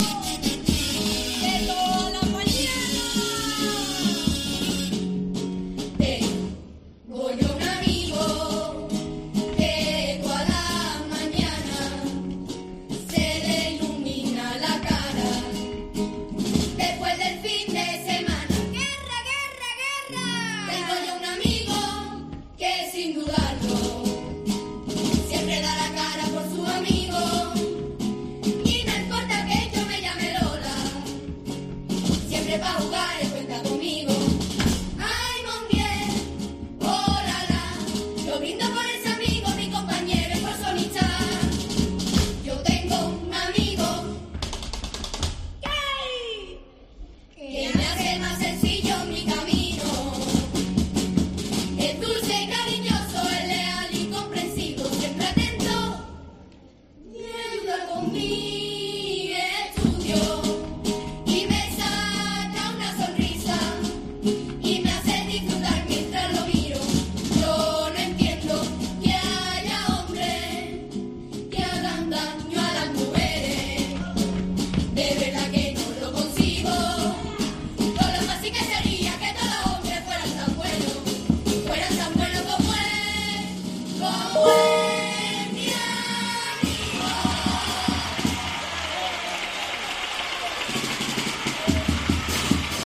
El pasodoble de Las lolas lolitas
Carnaval